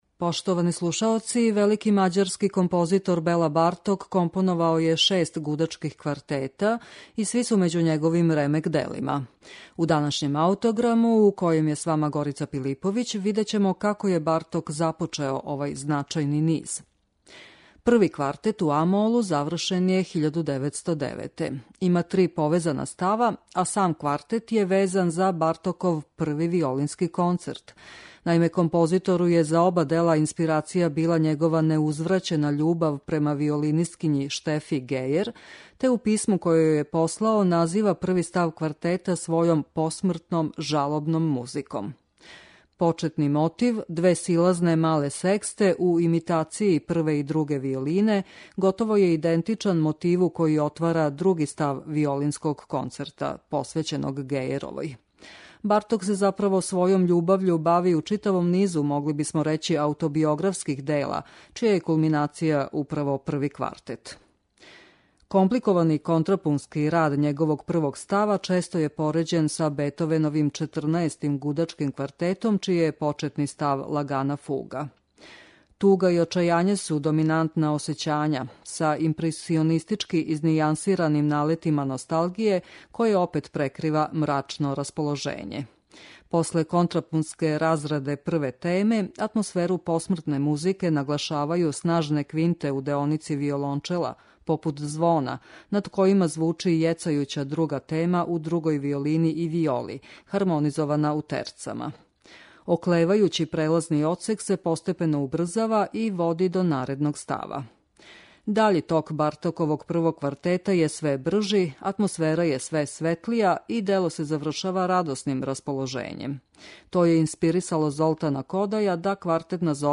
ПРВИ ГУДАЧКИ КВАРТЕТ БЕЛЕ БАРТОКА